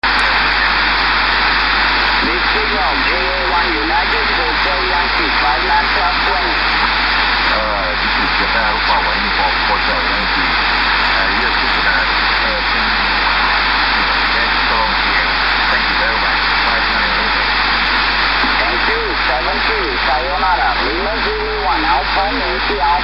Япония-Болгария, хорого различимы позывные обоих корреспондентов.
Принято в Москве, на Деген 1103 (без всяких модификаций).
7082 Мгц, диапазон в это время шумит здесь сильно.